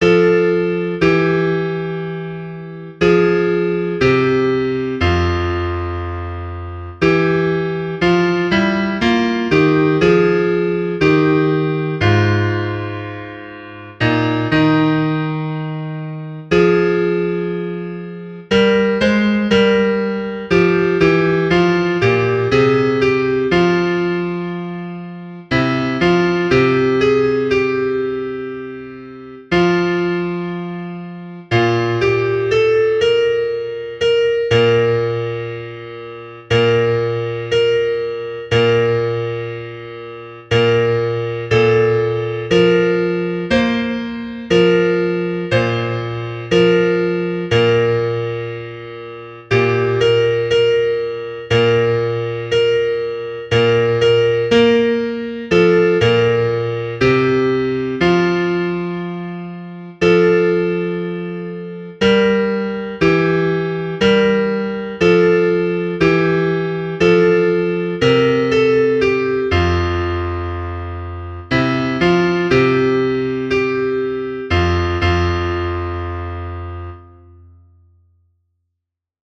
Choral
Key: F Major